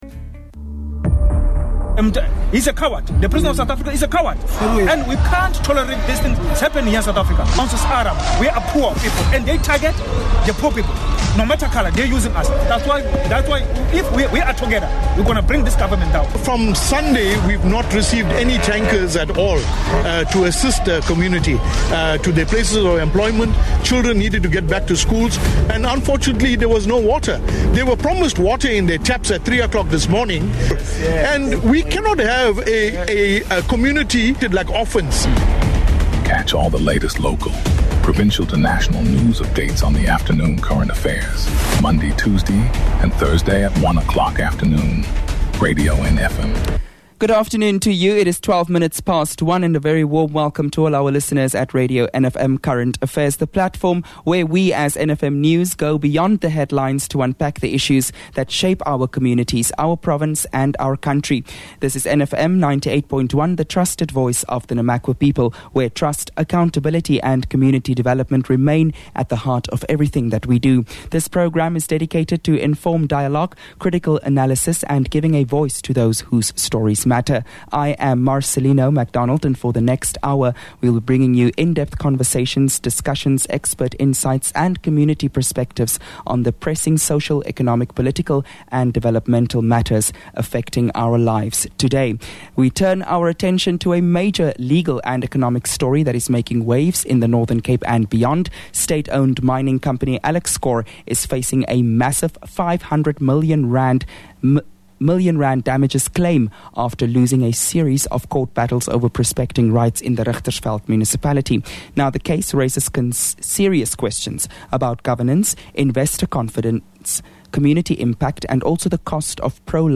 Current Affairs